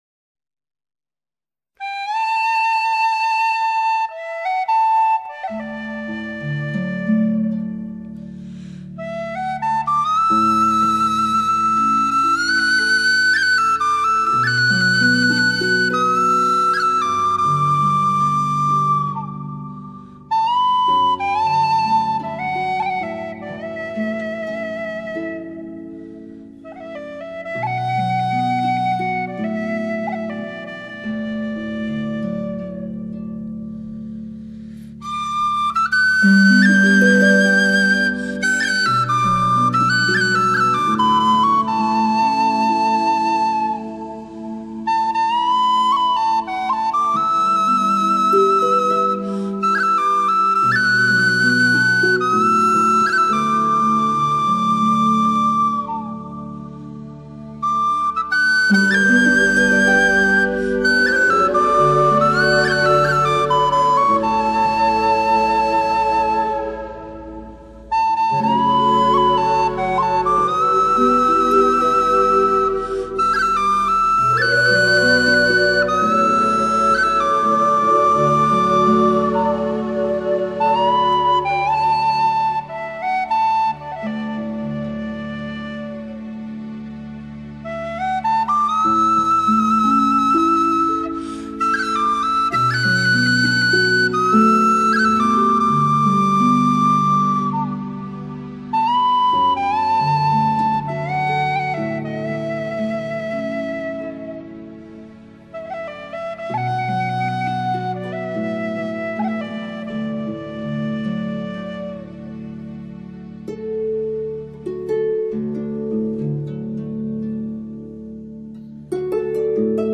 爱尔兰音乐 在欧洲大西洋的边缘、与大不列颠岛隔海相望有一个美丽岛国爱尔兰。